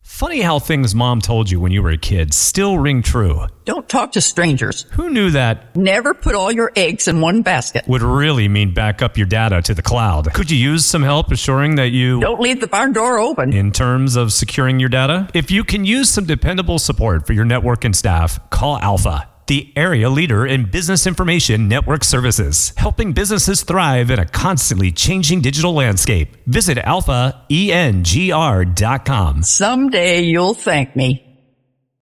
Have you heard our commercial on the new WRNR?